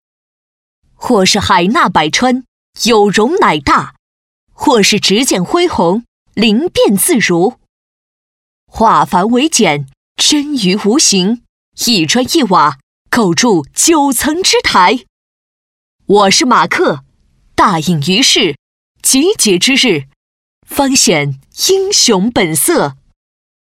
女国126_动画_角色_战士.mp3